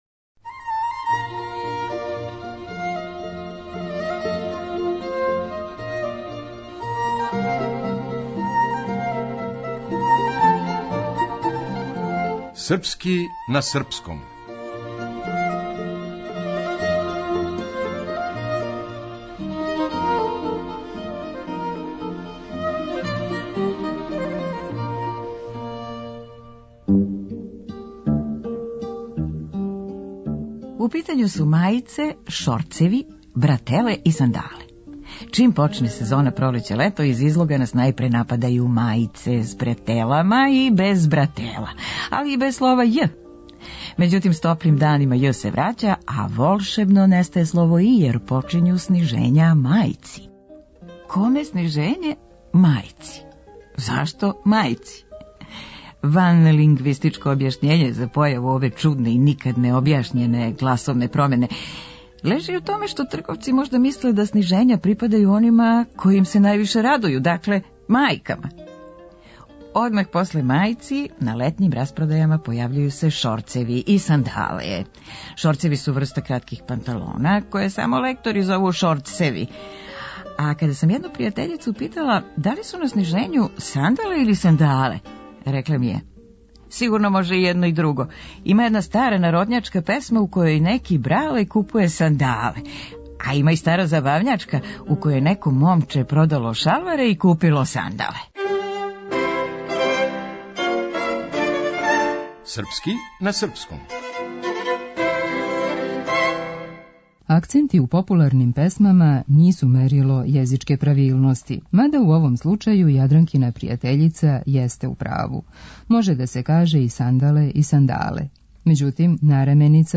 Драмска уметница: